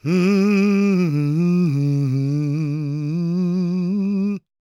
GOSPMALE335.wav